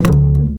DoubleBass 6 F.wav